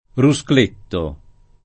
[ ru S kl % tto ]